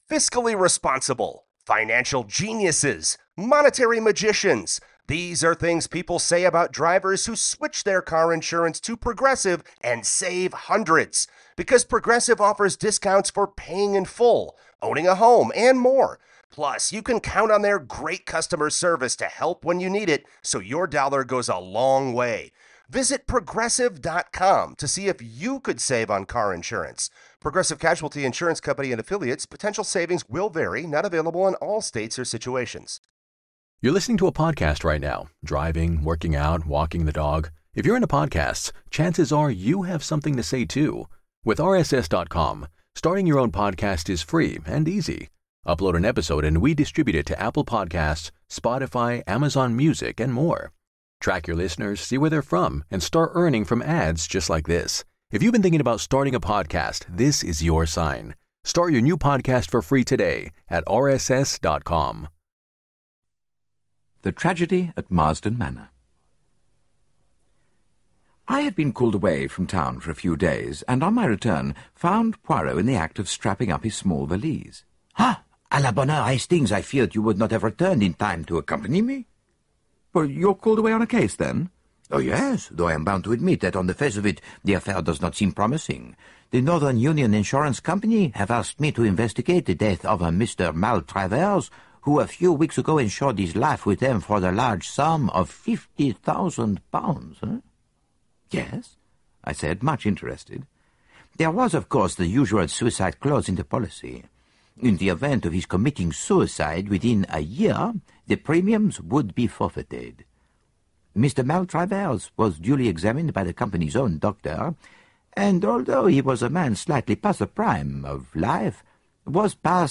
Agatha Christie - Hercule Poirot (Audiobook Collection)